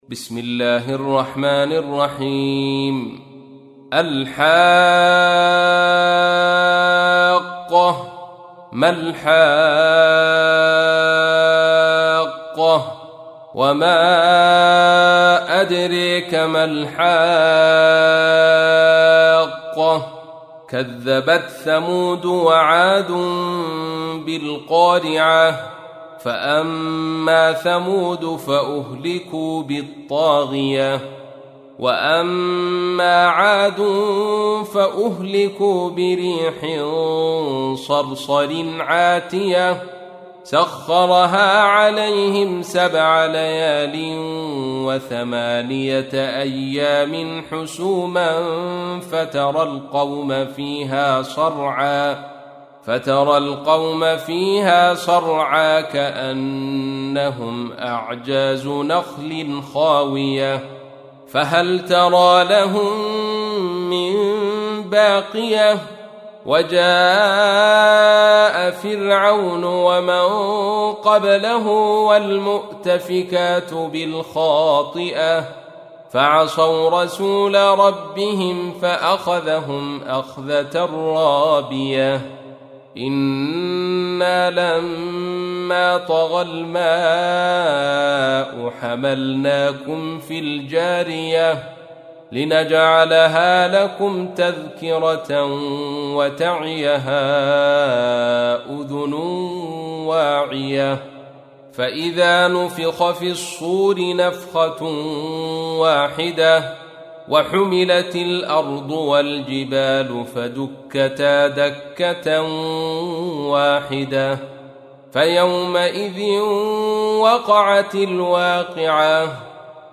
تحميل : 69. سورة الحاقة / القارئ عبد الرشيد صوفي / القرآن الكريم / موقع يا حسين